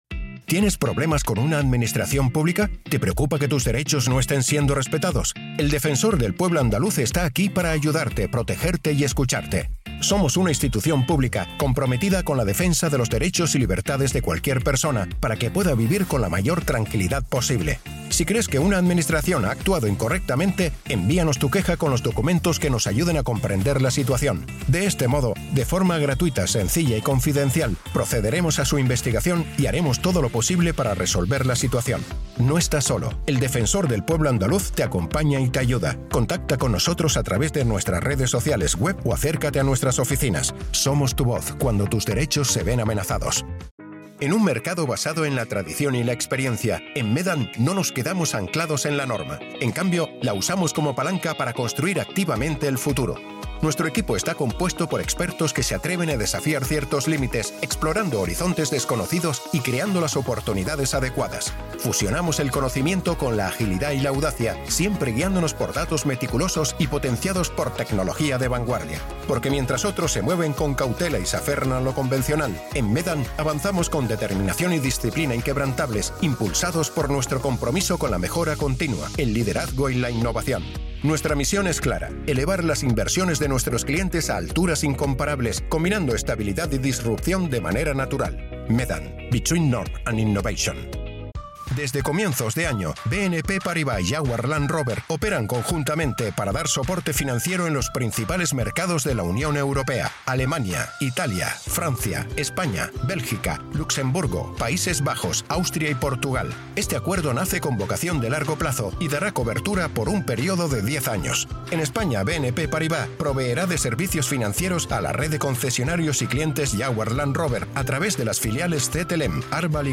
Corporate demo reel
castilian
Middle Aged
corporate demo reel.mp3